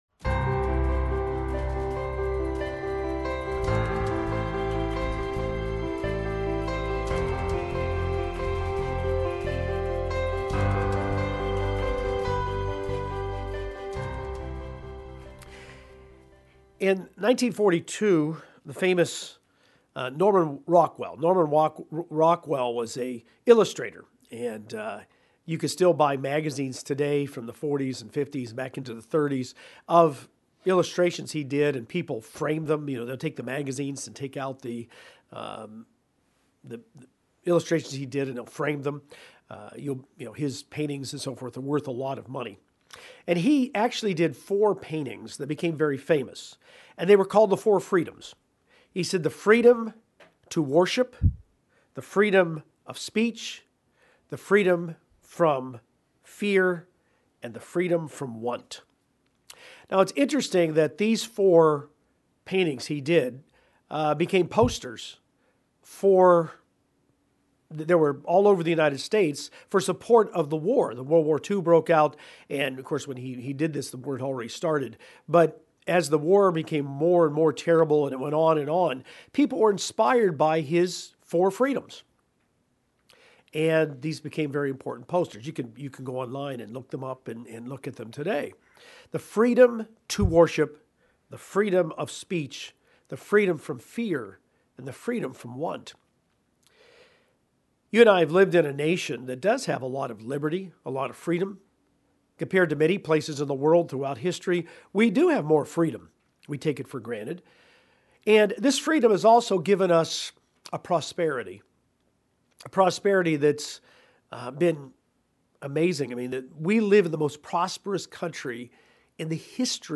Four aspects of the liberty we receive when we partake in the New Covenant, and live Jesus Christ's life in us. Given on the Last Day of Unleavened Bread 2020.